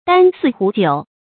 簞食壺酒 注音： ㄉㄢ ㄙㄧˋ ㄏㄨˊ ㄐㄧㄨˇ 讀音讀法： 意思解釋： 簞：盛飯竹器。